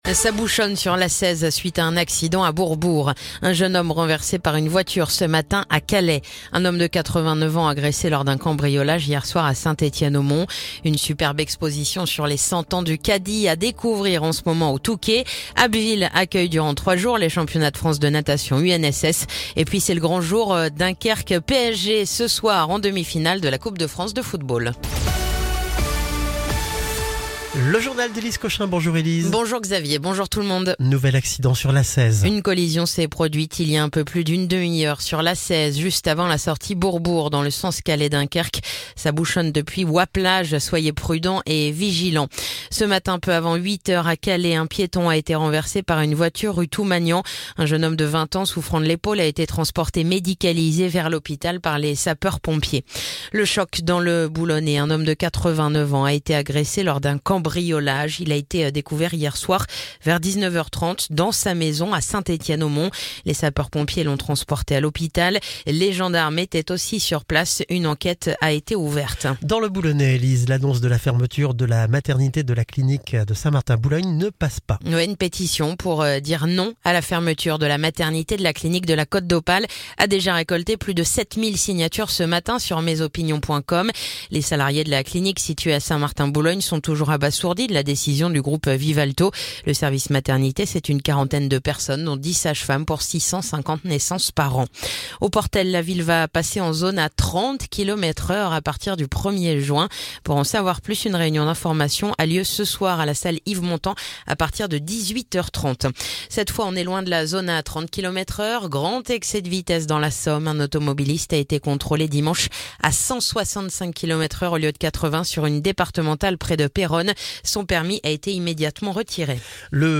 Le journal du mardi 1er avril